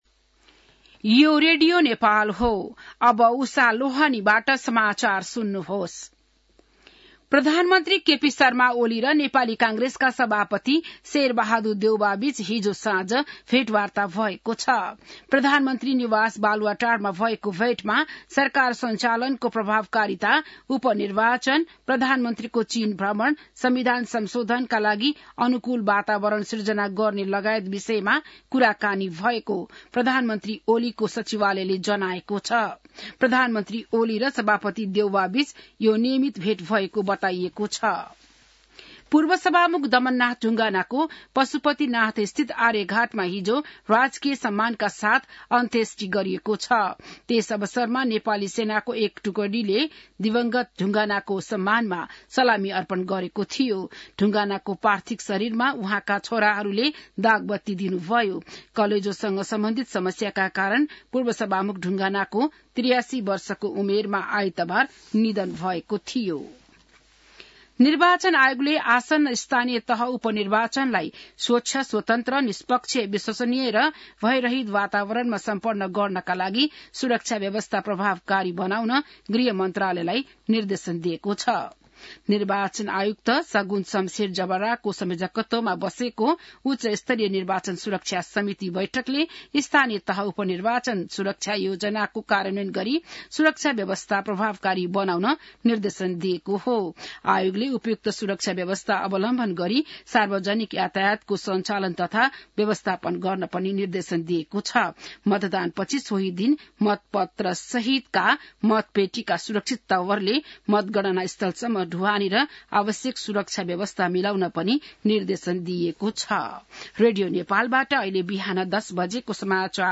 बिहान १० बजेको नेपाली समाचार : ५ मंसिर , २०८१